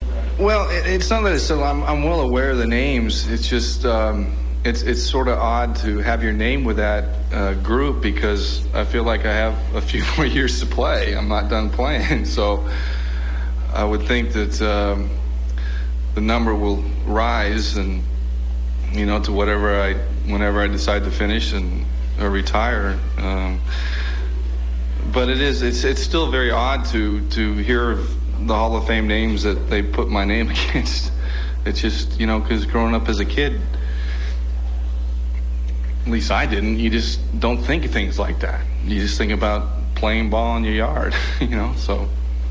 RA Audio: Press Conference on "Being with the Greats" - 05 AUG 1999